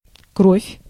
Ääntäminen
Ääntäminen Tuntematon aksentti: IPA: /ˈkrofʲ/ Haettu sana löytyi näillä lähdekielillä: venäjä Käännös Konteksti Ääninäyte Substantiivit 1. blood lääketiede US UK 2. gore Translitterointi: krov.